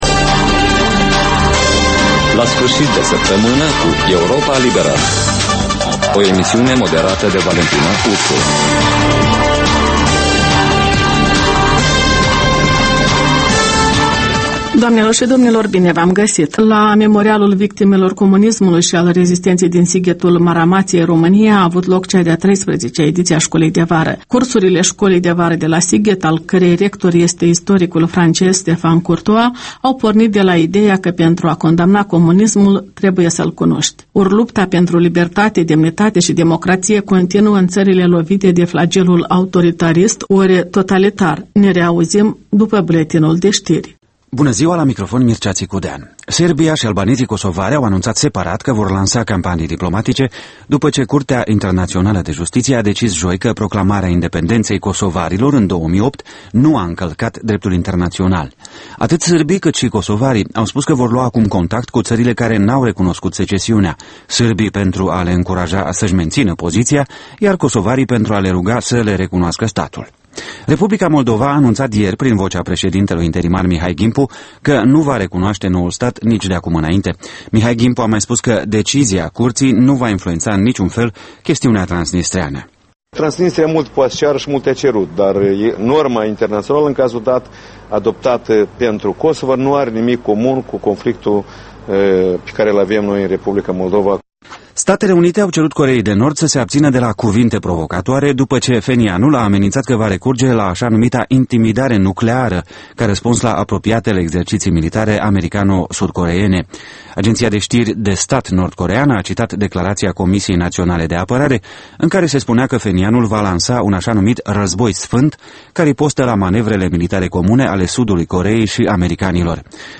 O emisiune în reluare cu un buletin de ştiri actualizat, emisiunea se poate asculta şi pe unde scurte